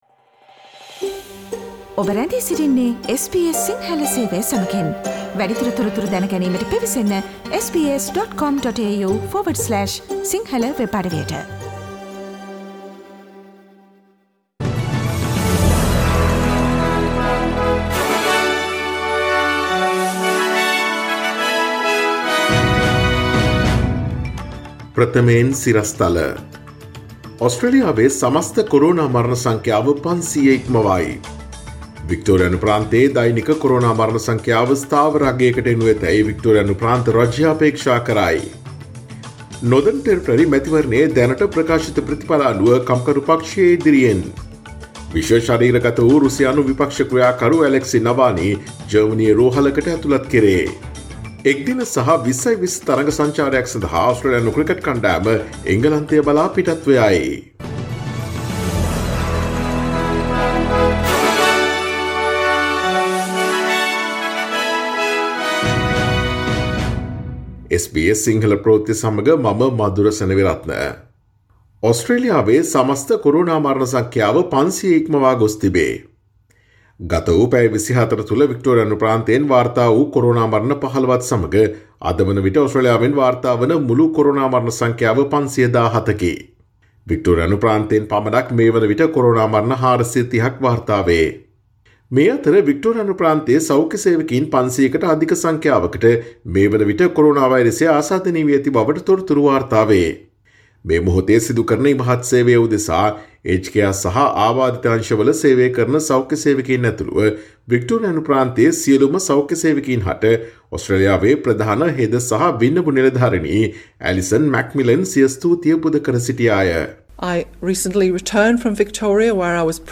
Daily News bulletin of SBS Sinhala Service: Monday 24 August 2020